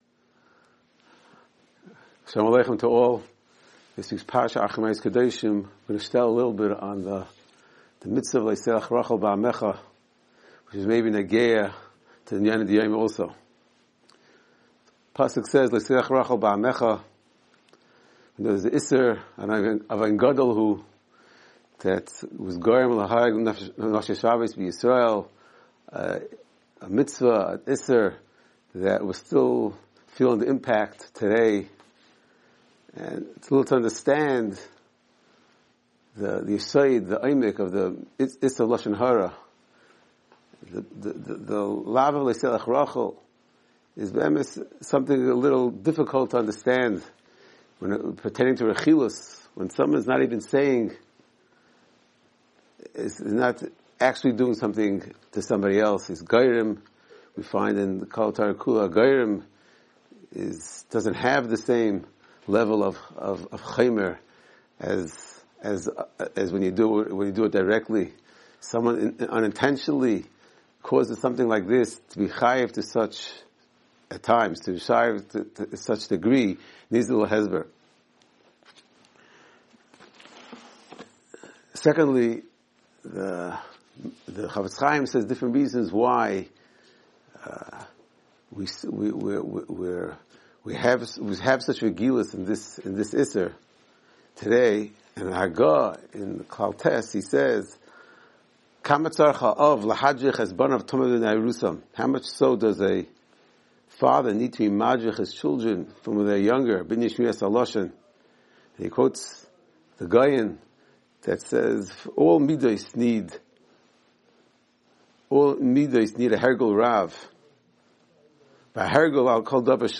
Weekly Alumni Shiur Acharei-Kedoshim 5785